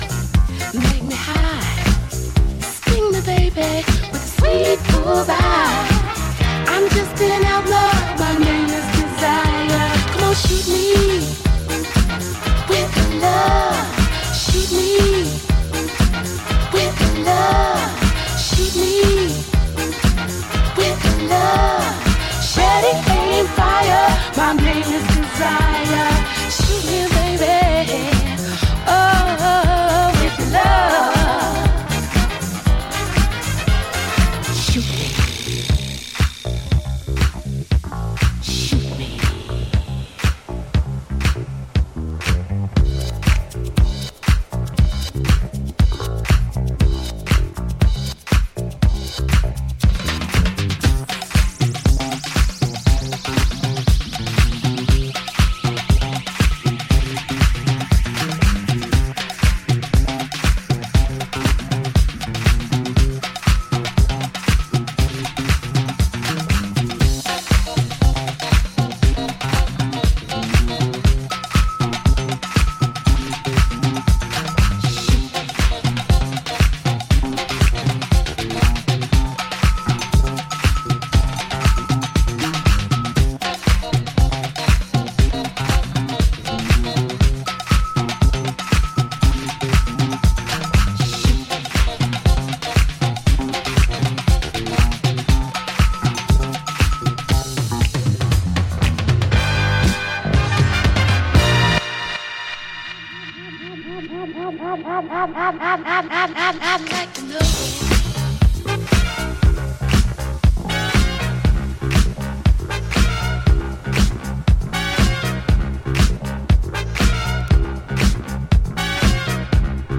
'78 disco classic